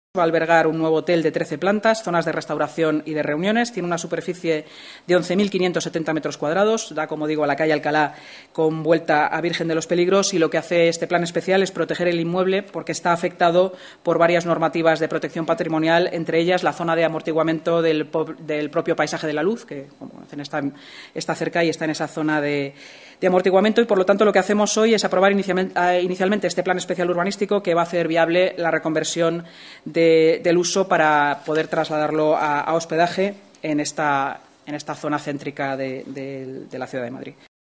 Nueva ventana:La vicealcaldesa y portavoz municipal, Inma Sanz